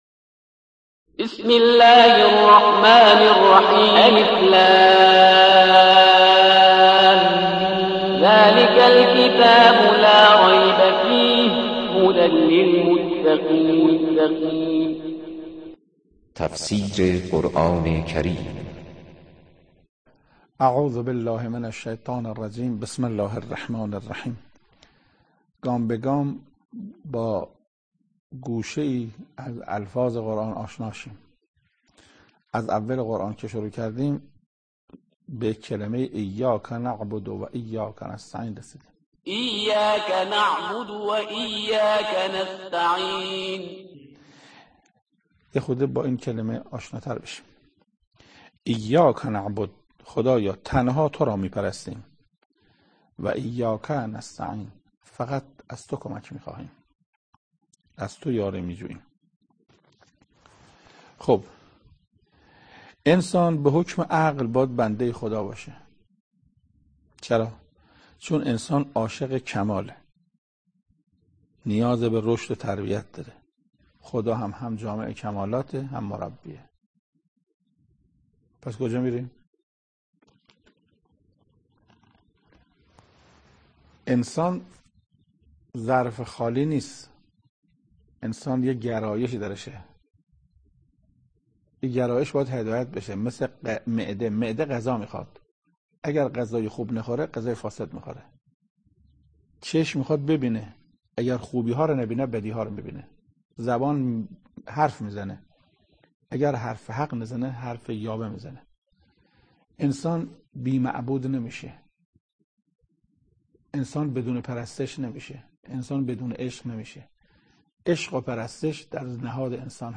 تفسیر پنجمین آیه از سوره مبارکه حمد توسط حجت الاسلام استاد محسن قرائتی به مدت 16 دقیقه
سخنرانی محسن قرائتی